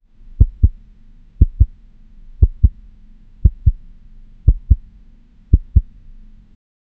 a beating heart
a-beating-heart-ofxlltnj.wav